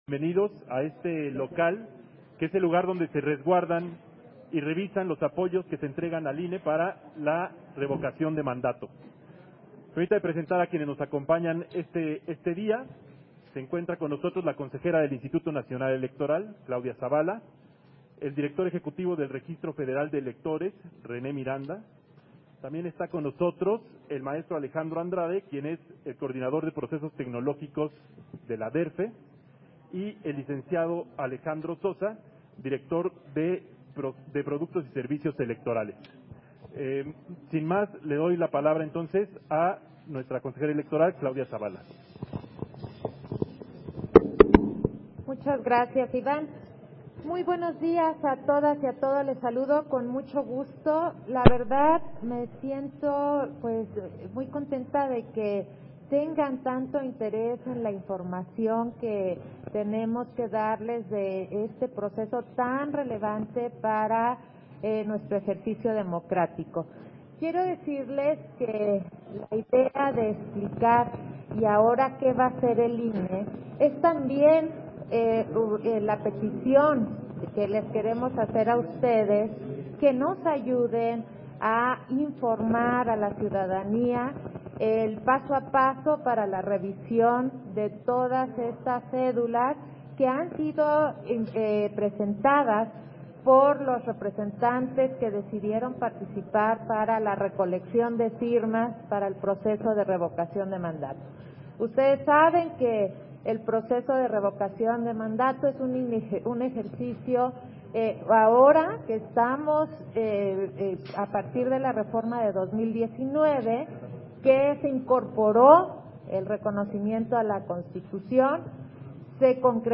211221_AUDIO_CONFERENCIA-DE-PRENSA - Central Electoral